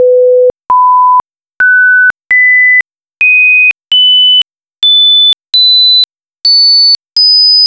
Listen to 500-5,000hz tones in increments of 500hz and pauses for 1,000hz intervals: